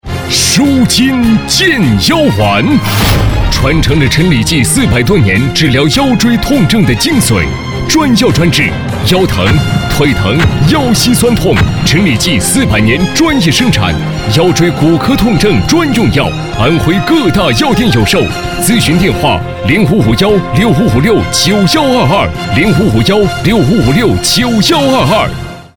电视购物配音
男国443_广告_电购_舒筋腱腰丸.mp3